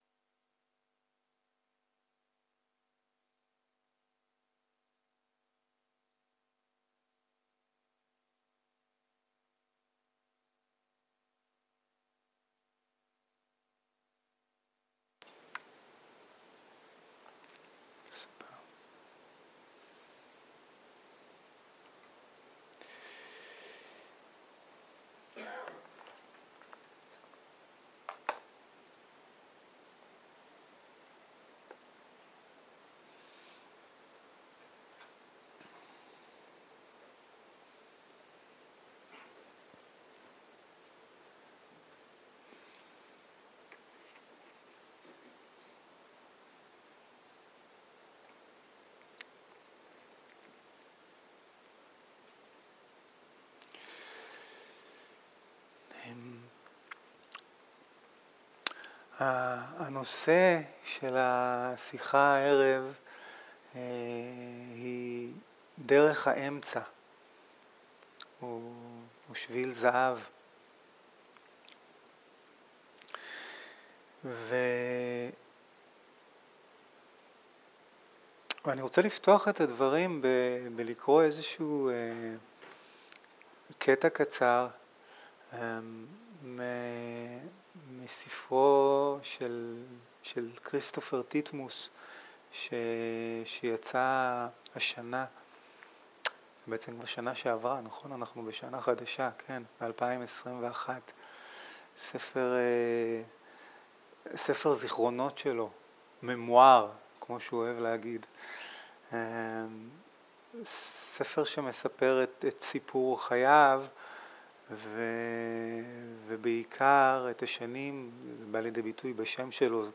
שיחת דהרמה - דרך האמצע שביל הזהב
Dharma Talks